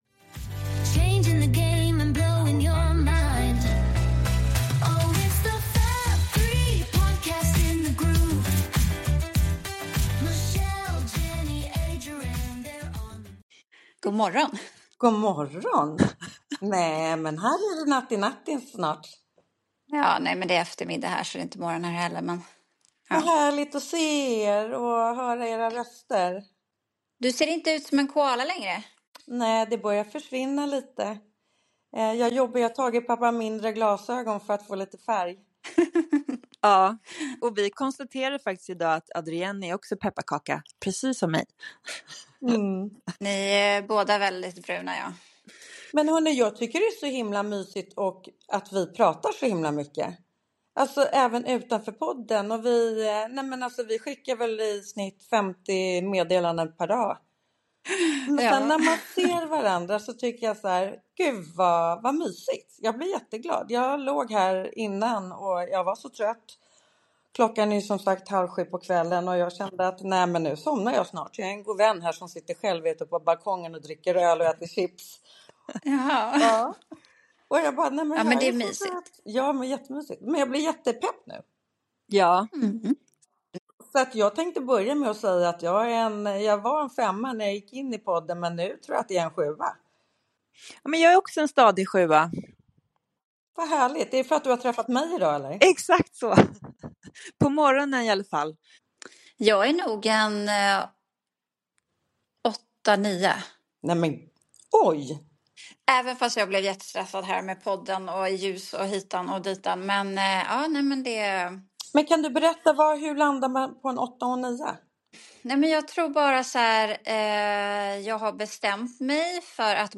Tre röster, oändliga berättelser. ärliga samtal, massa skratt och 100% fab vibes.